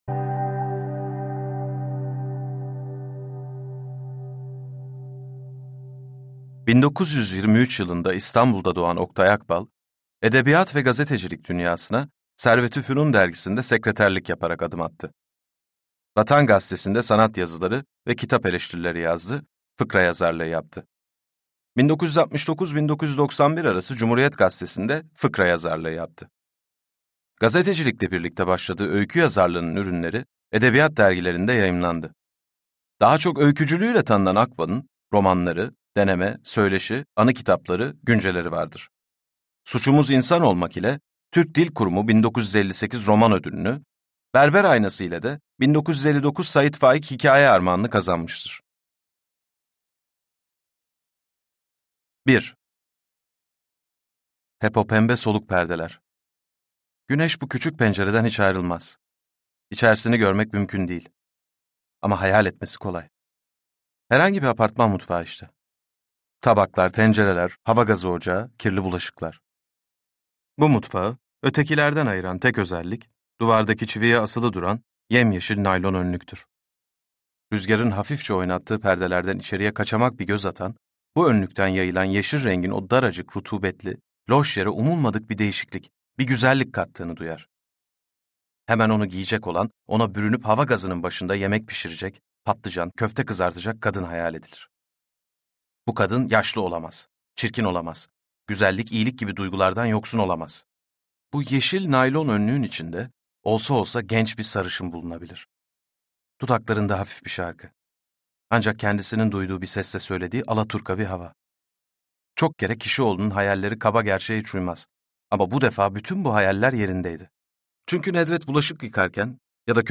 Suçumuz İnsan Olmak - Seslenen Kitap
Seslendiren